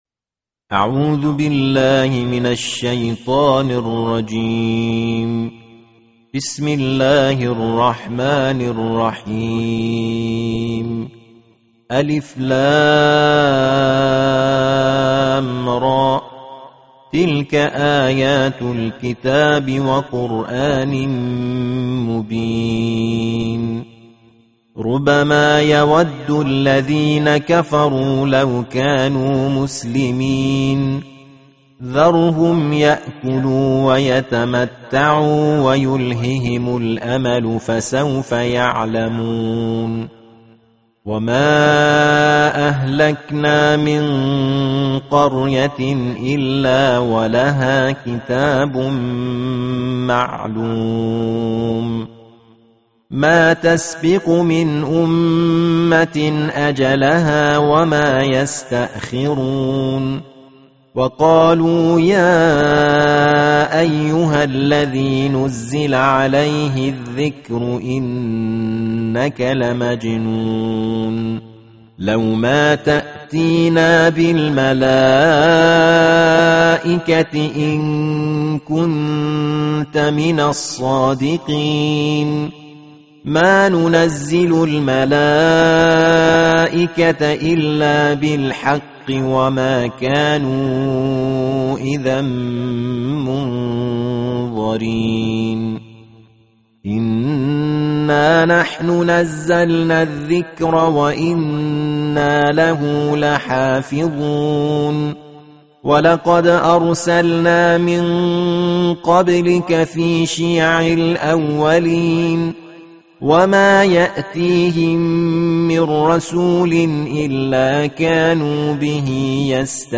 Tartil Juz Keempatbelas Alquran